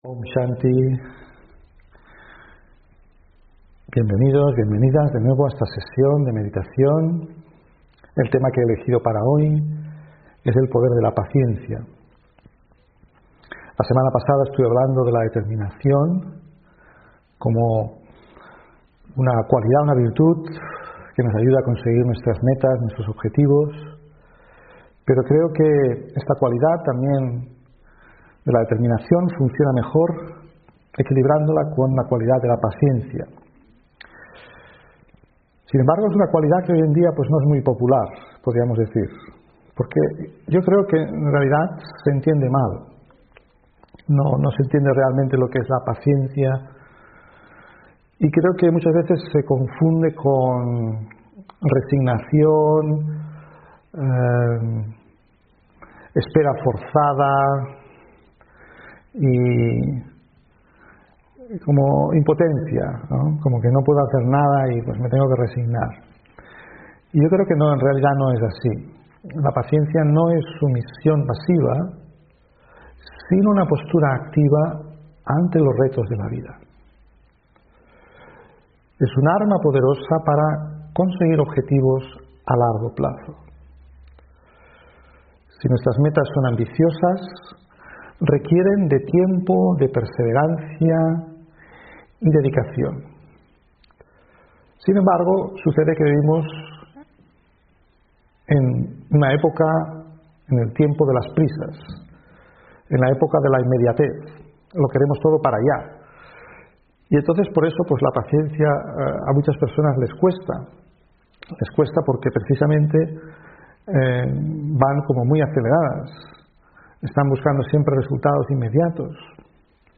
Meditación Raja Yoga: El poder de la paciencia (2 Noviembre 2020) On-line desde Mallorca